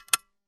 Stapler Click
A desktop stapler being pressed down with a satisfying mechanical click and spring return
stapler-click.mp3